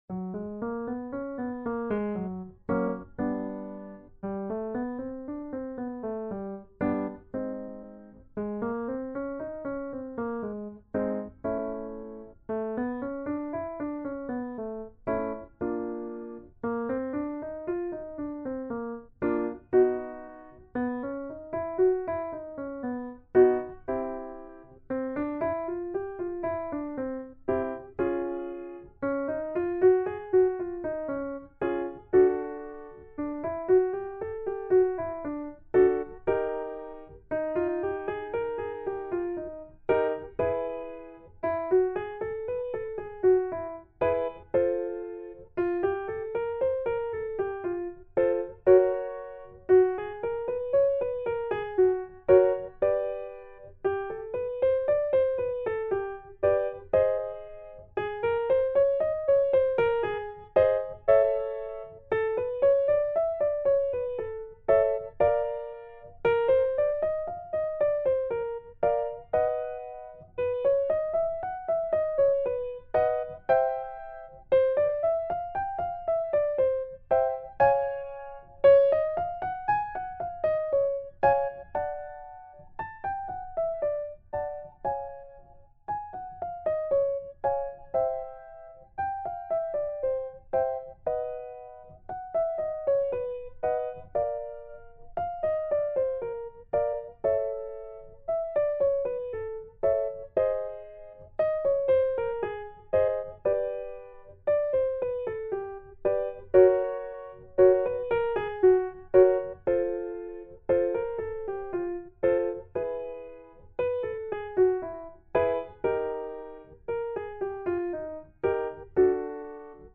fast SCALES.mp3